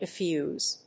発音記号
• / ɪfjúːz(米国英語)